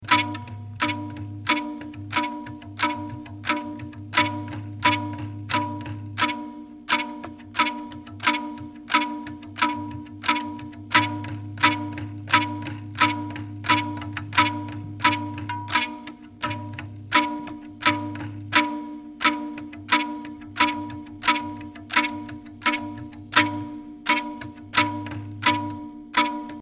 bamboo tube zither the instrument is played rhythmically to accompany dancing 478KB
bamboo zither.mp3